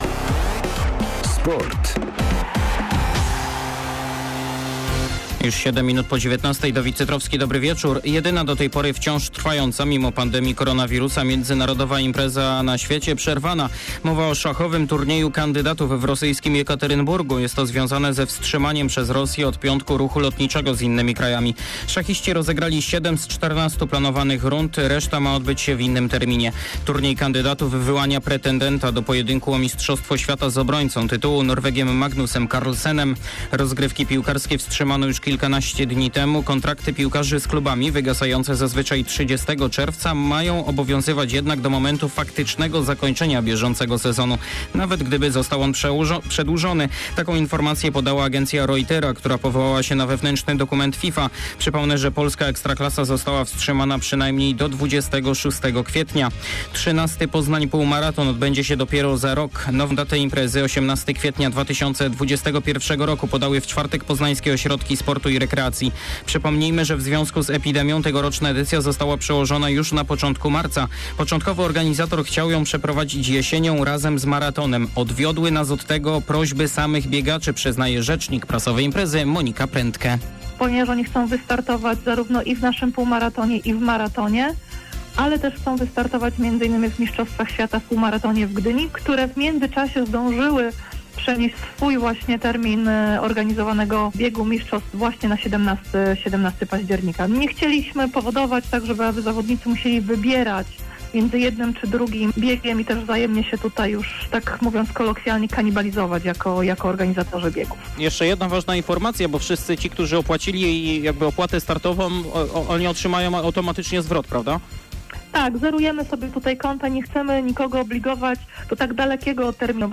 26.03. SERWIS SPORTOWY GODZ. 19:05